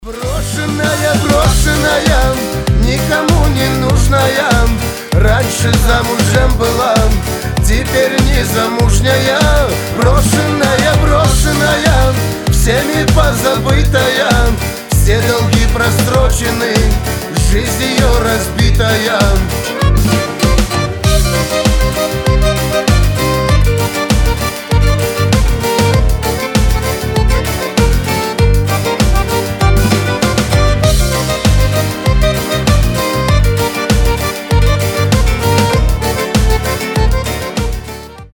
• Качество: 320, Stereo
цыганские
кавказские